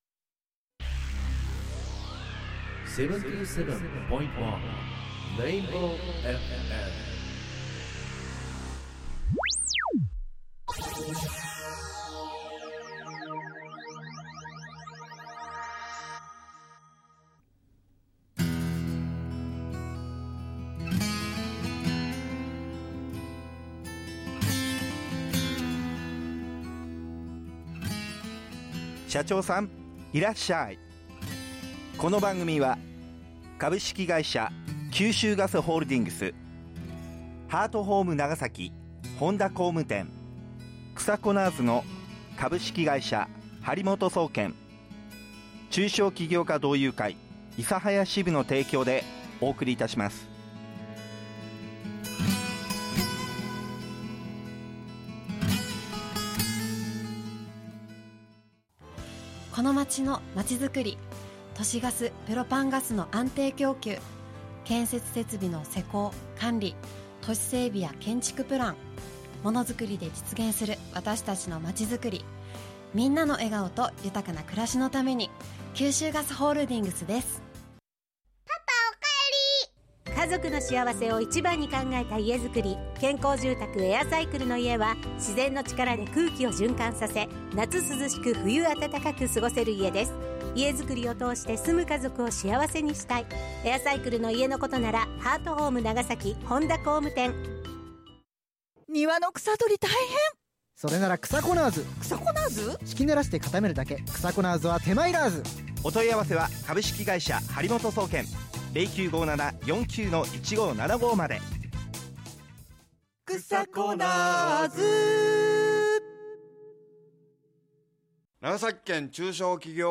十八番の森進一のモノマネから事業年表のお話し、今後の事業承継のことなど和やかな雰囲気の中お話しいただきました。